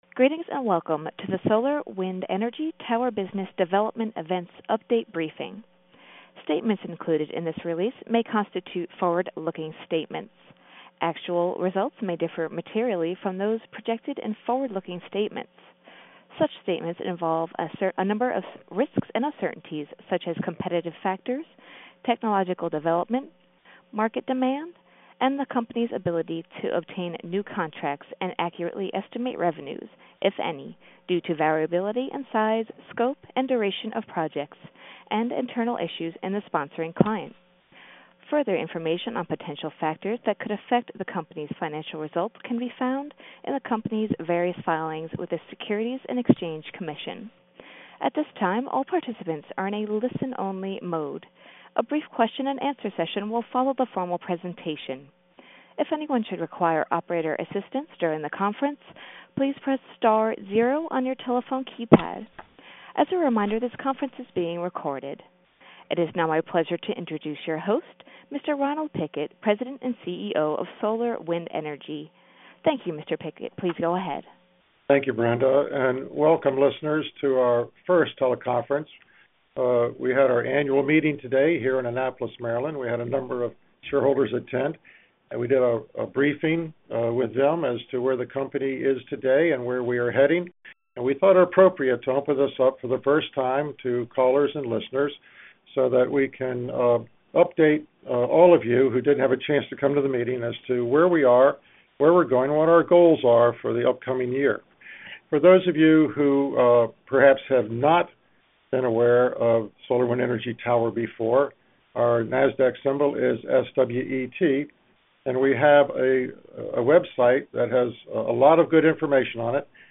SWET_Investor_Call_Dec2013.mp3